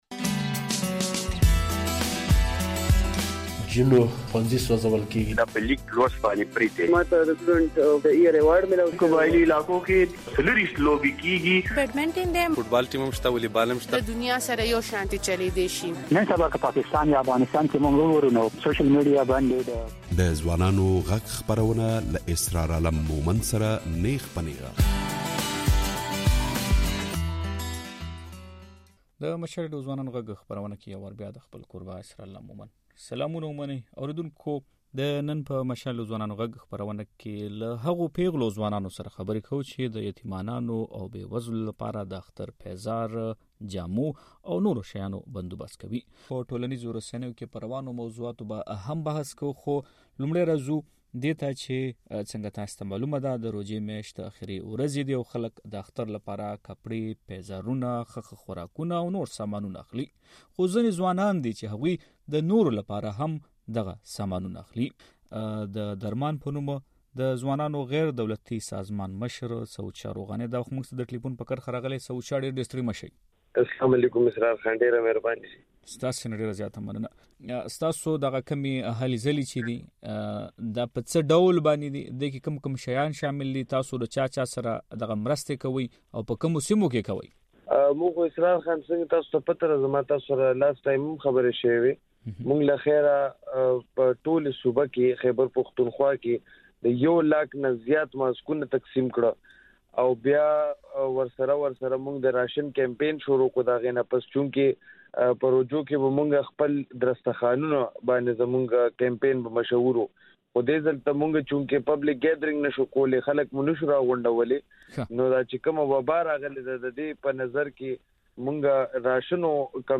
د دې اونۍپه ځوانانو غږ خپرونه کې مو له هغو پېغلو او ځوانانو سره خبرې کړې چې د يتيمانو او بې وزلو لپاره د اختر د پېزار، جامو او نورو شيانو بندوبست کوي.